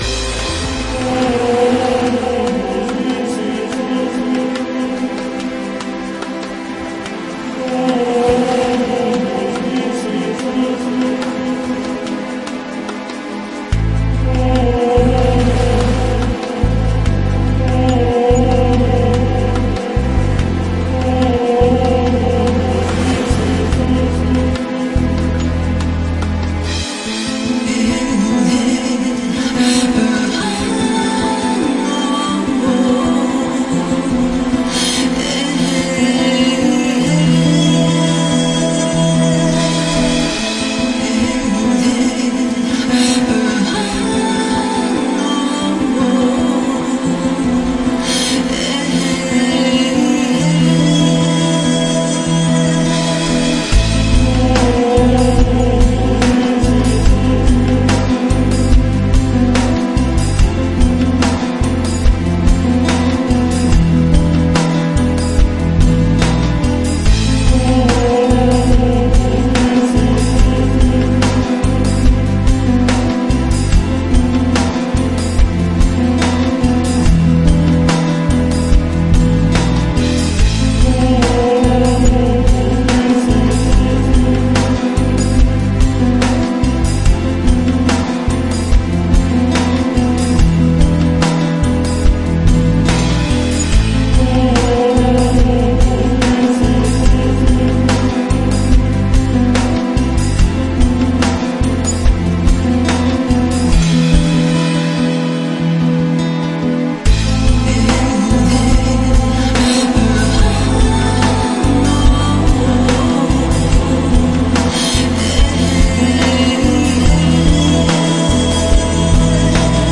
Medieval Mix